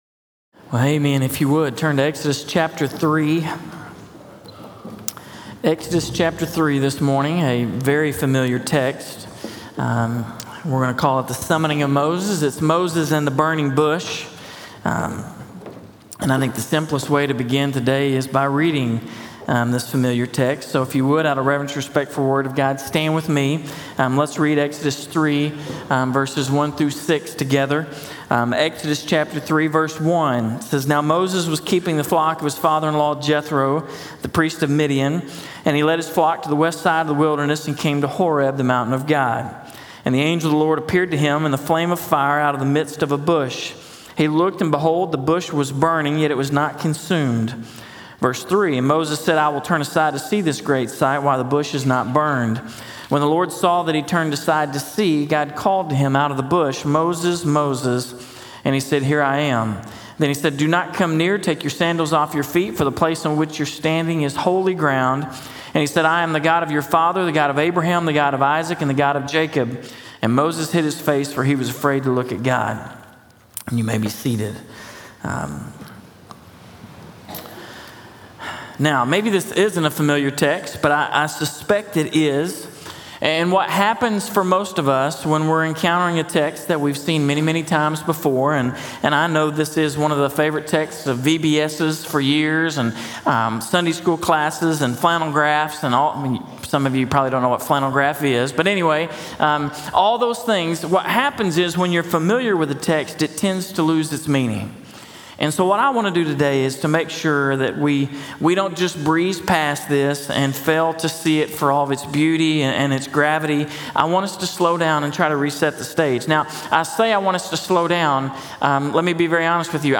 In this message, "The Summoning of Moses," from Exodus 3:1-10, we move into week six of our sermon series, “The Deliverer.”